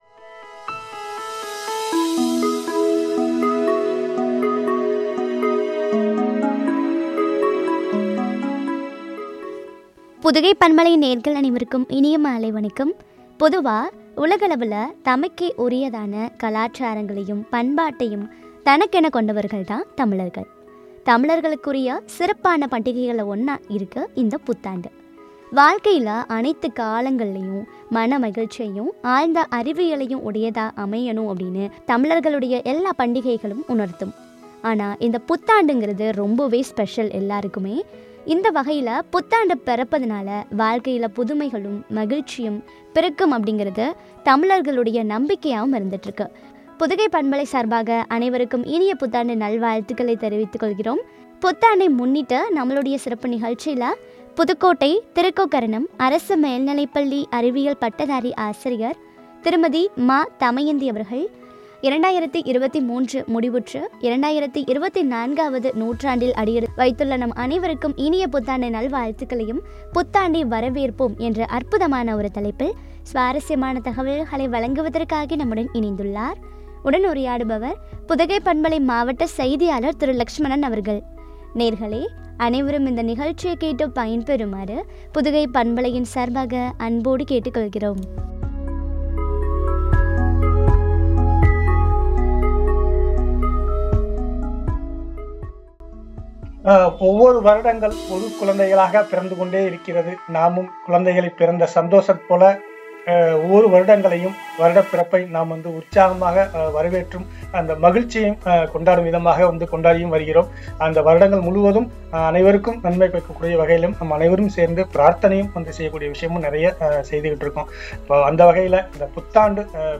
2024 புத்தாண்டை வரவேற்போம், குறித்து வழங்கிய உரையாடல்.